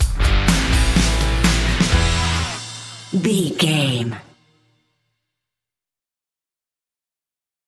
Mixolydian
electric guitar
bass guitar
drums
hard rock
aggressive
energetic
intense
nu metal
alternative metal